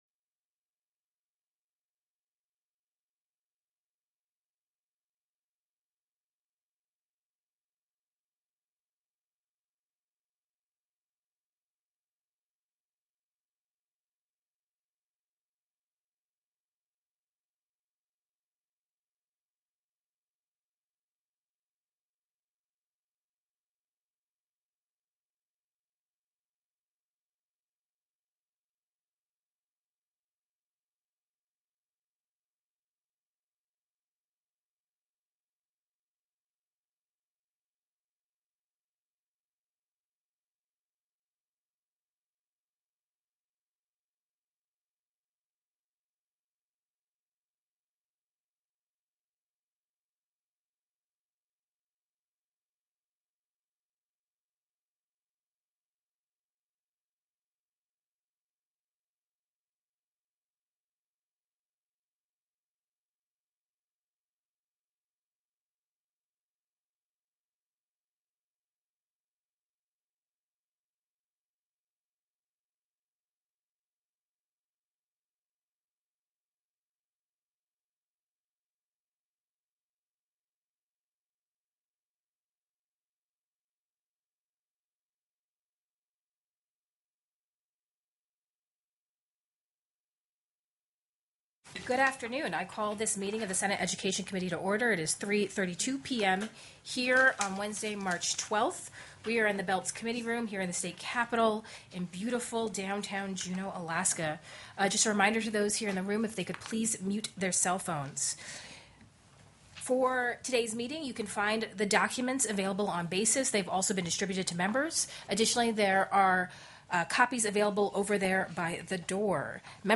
The audio recordings are captured by our records offices as the official record of the meeting and will have more accurate timestamps.
SB 18 COMMUNICATION DEVICES IN PUBLIC SCHOOLS TELECONFERENCED Heard & Held -- Invited & Public Testimony --